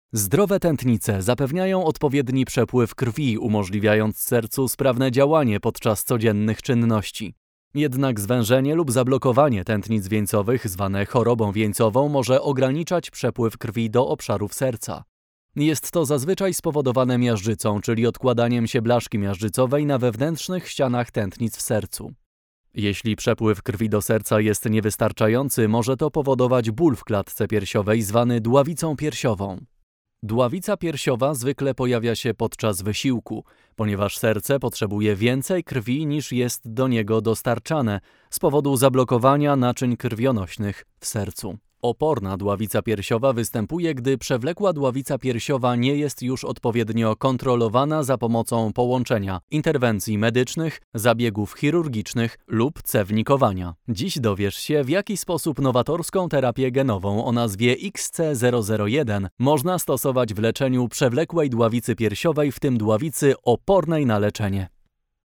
Narração Médica
Eu gravo todos os dias no meu próprio estúdio em casa. Minha voz é percebida como quente, fresca e dinâmica.
As pessoas percebem minha voz como calorosa, suculenta, amigável, suave, otimista e inspiradora.
Eu uso microfone Neumann TLM 103; interface RME BabyFace Pro FS.
BarítonoProfundoAlto